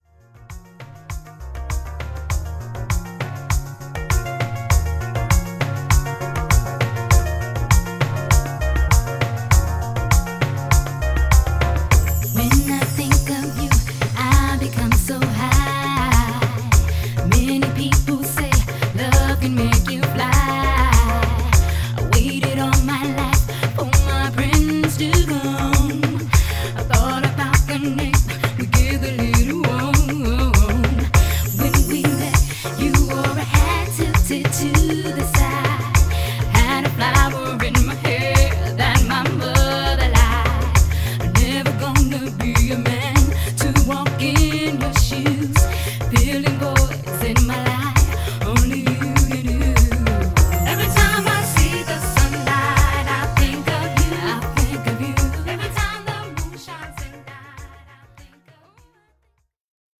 アーバンなミッド・ブギー好曲